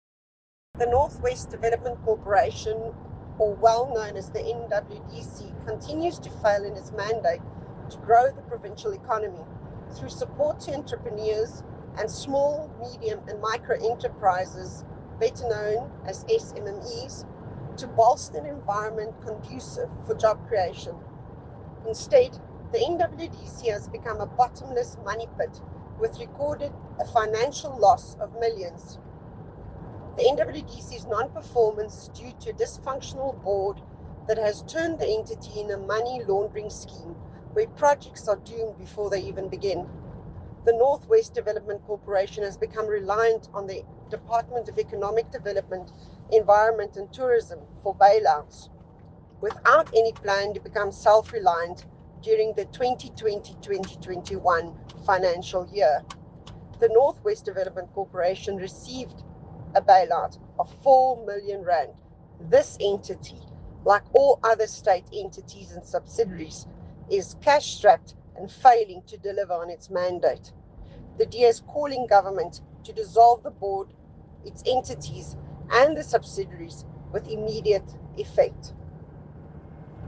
Issued by Jacqueline Theologo MPL – DA North West Spokesperson on Economic Development, Environment, Conservation and Tourism
Note to Broadcasters: Find linked soundbites in